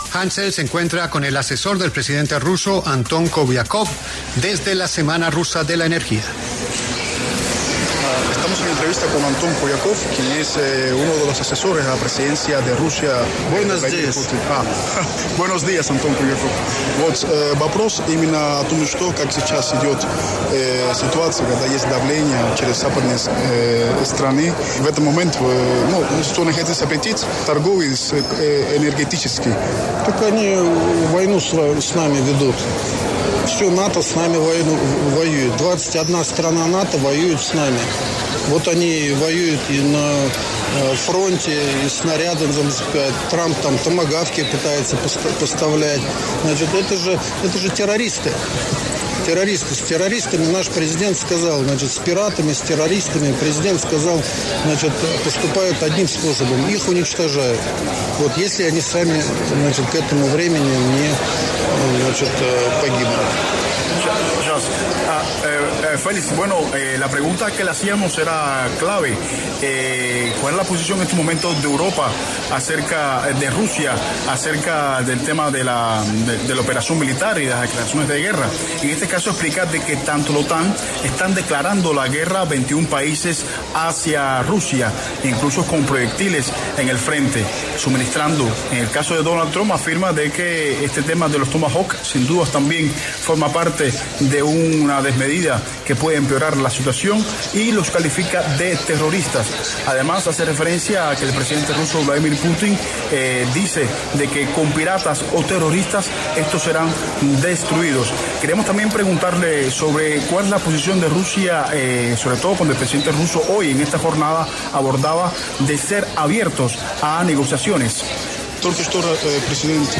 La W conversó con el asesor del presidente ruso, Anton Kobiakov, quien afirmó que toda la OTAN les ha declarado la guerra.